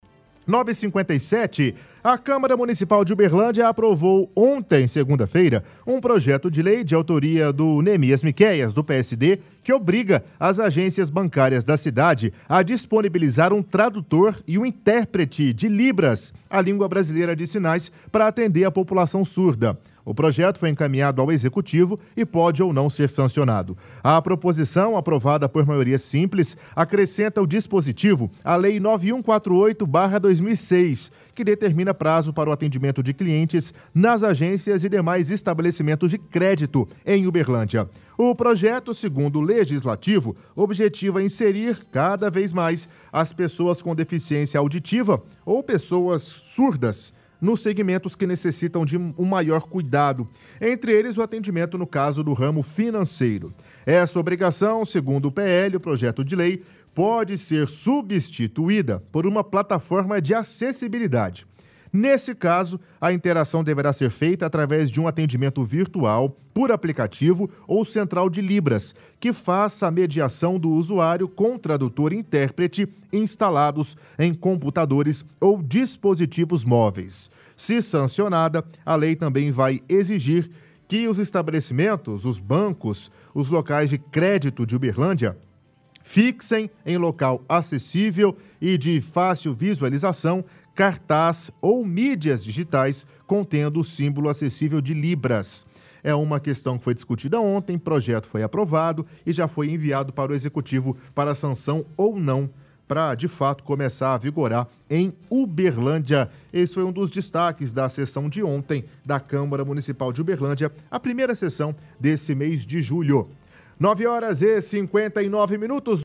– Apresentador lê reportagem informando que câmara municipal aprovou ontem projeto que obriga as agencias bancárias a manterem um interprete de libras. Projeto segue para a aprovação do prefeito.